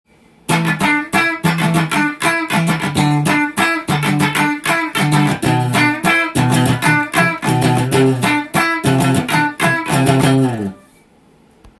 テレキャスターらしいシャキシャキした音がカッティングや
コード弾きに適した感じがします。
全く違和感なくカッティングできます。